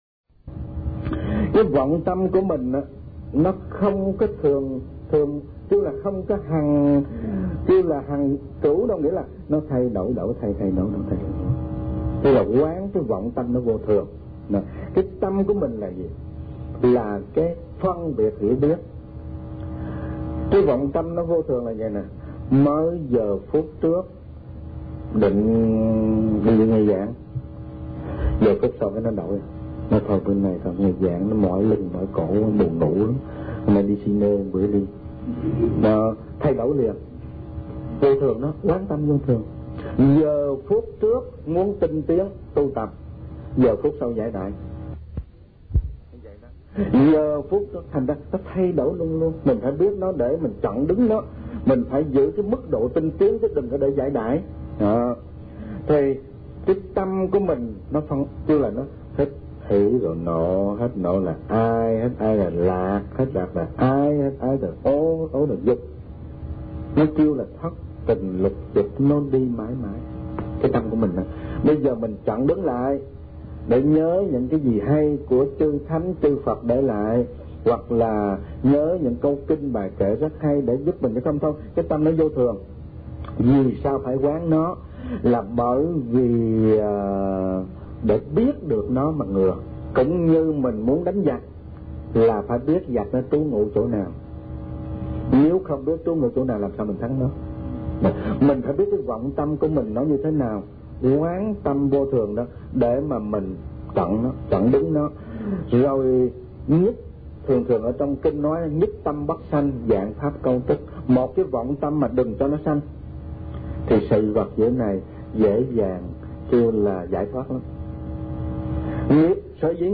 Kinh Giảng Tứ Diệu Đế